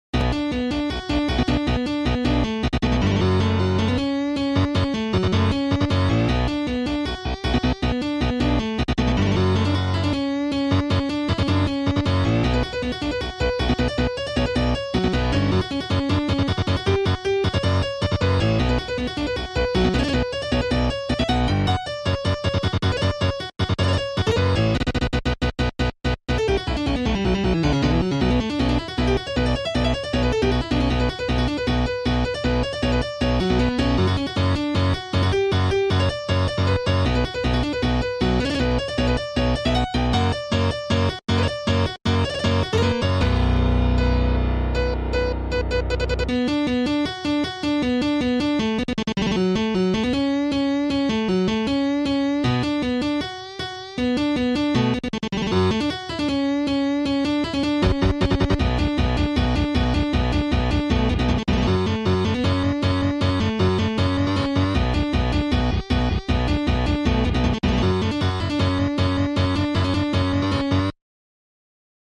boss theme ahh melody